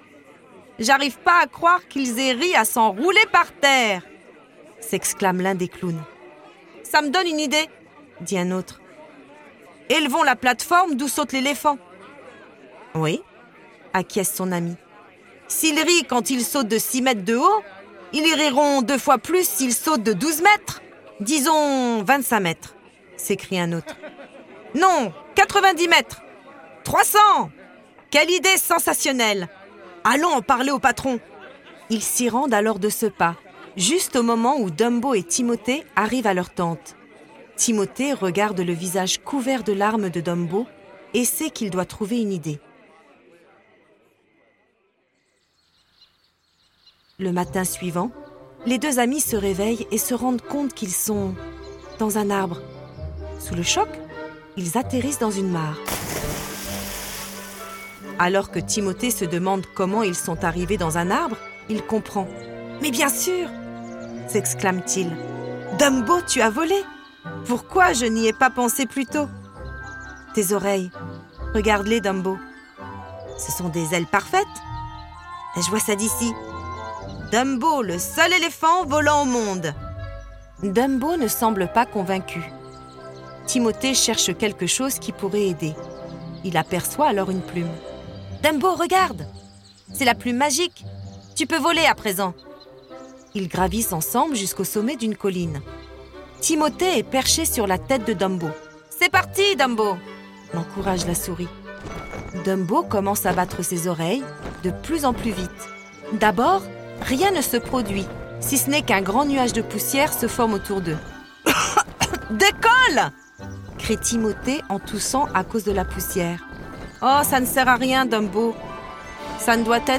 10 - Chapitre 10_ Dumbo - L'histoire à écouter_ Dumbo.flac